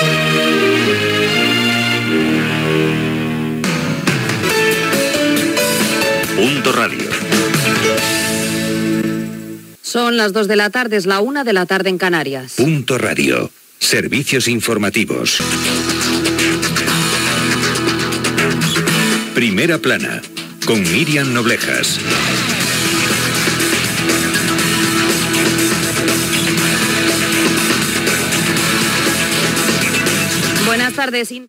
Indicatiu, hora, careta del programa Gènere radiofònic Informatiu